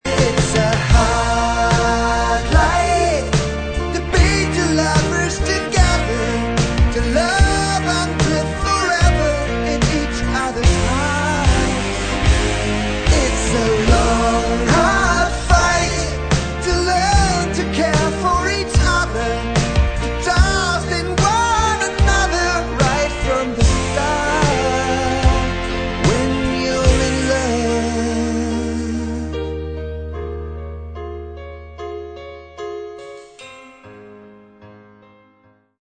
(2nd verse/chorus + piano middle part)
Lo-Fi Sound-Quality